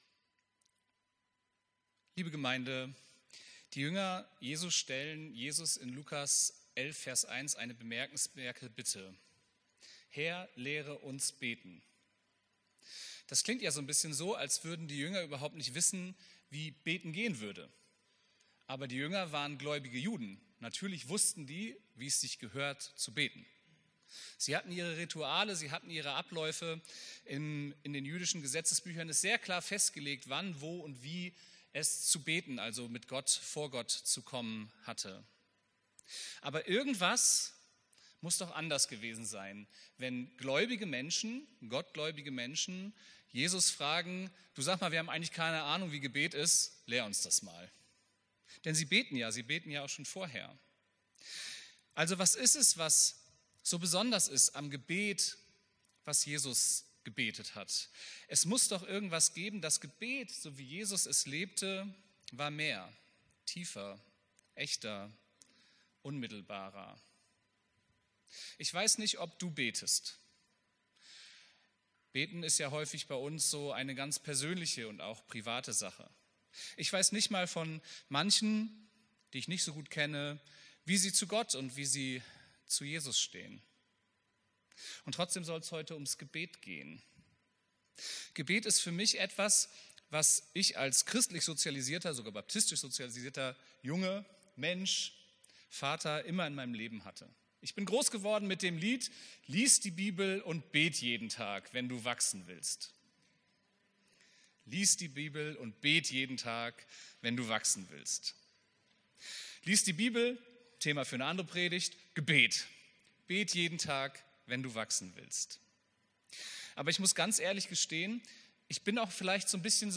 Predigt vom 17.08.2025